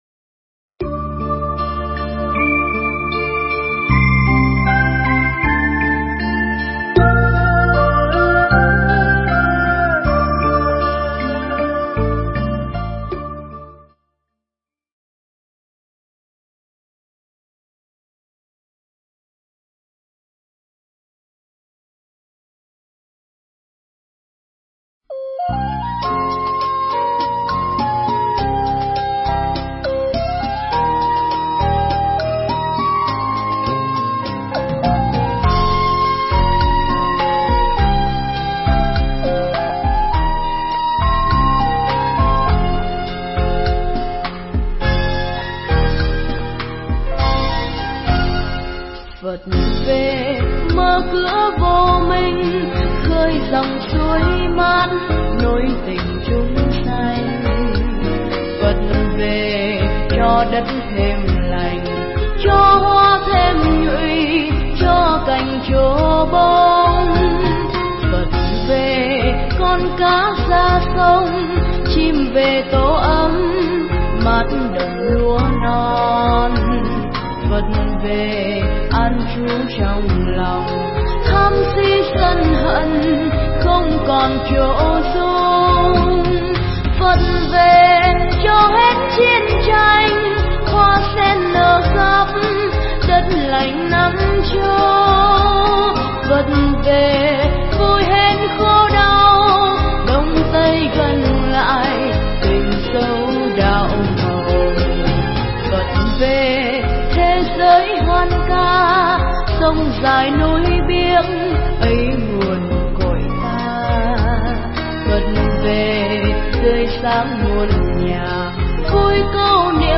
Nghe Mp3 thuyết pháp Tìm Cầu Hạnh Phúc